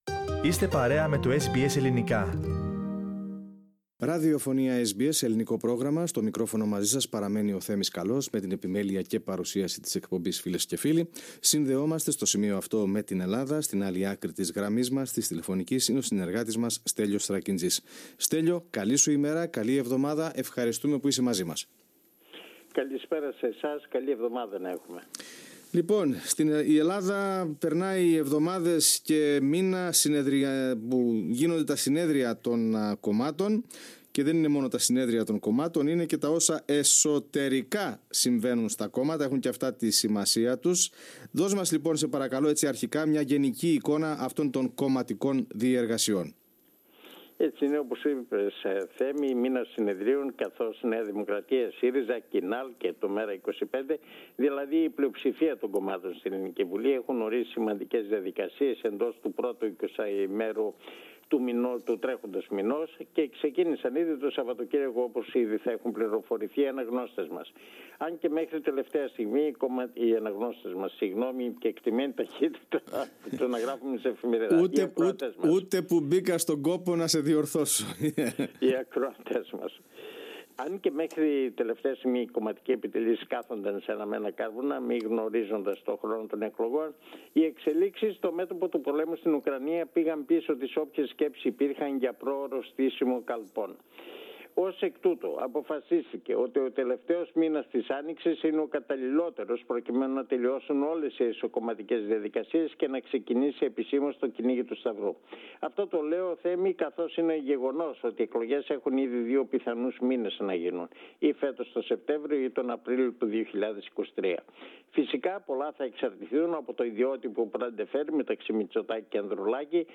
Ακούστε, ολόκληρη την ανταπόκριση από την Ελλάδα, πατώντας το σύμβολο στο μέσο της κεντρικής φωτογραφίας.